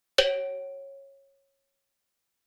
Звуки анимации